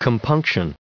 added pronounciation and merriam webster audio
922_compunction.ogg